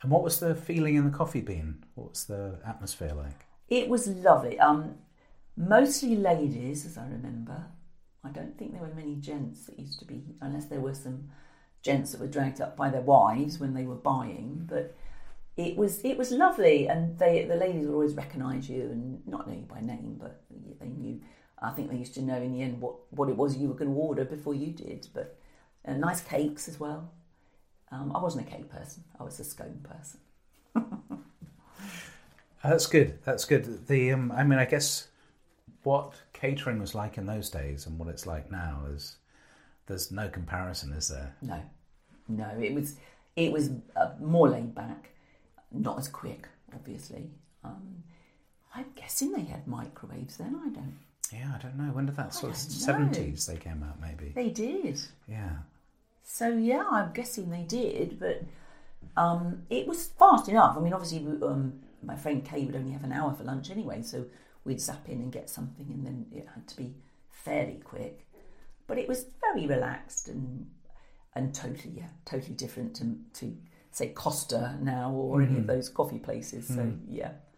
Packs' People Oral History project